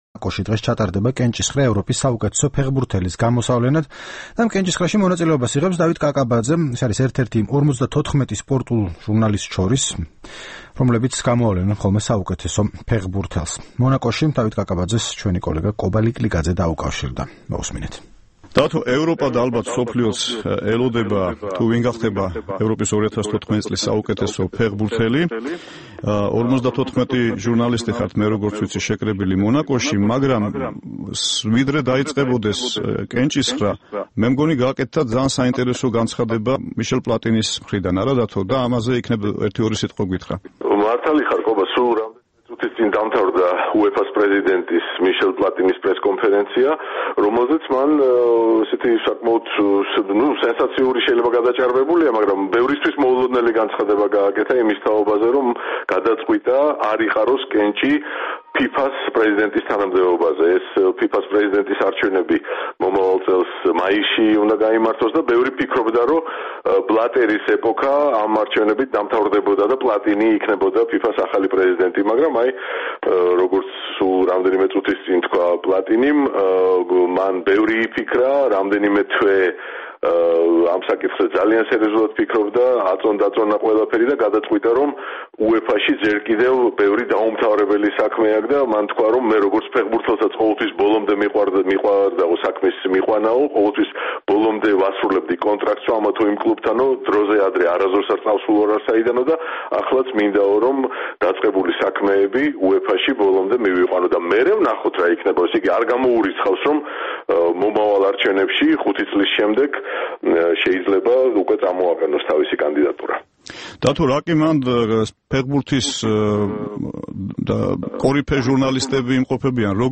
ტელეფონით.